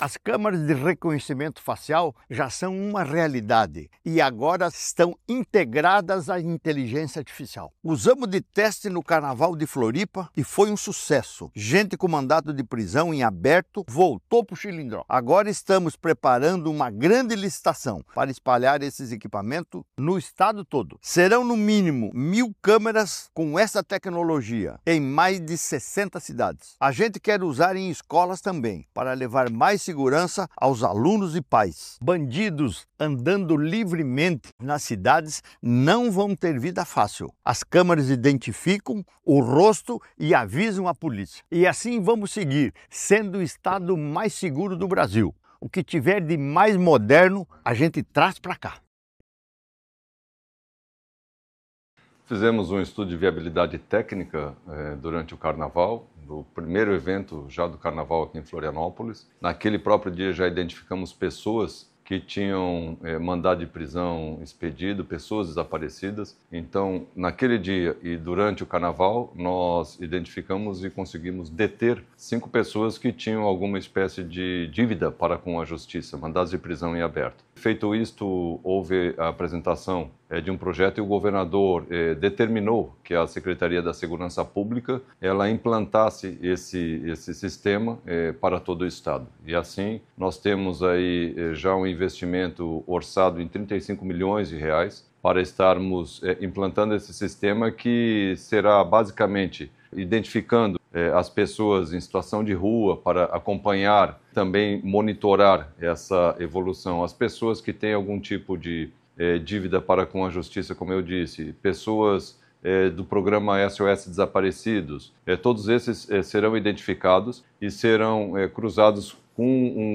SONORA – Governo do Estado reforça a segurança com sistema de monitoramento facial em 60 cidades catarinenses – GIRO SERTÃO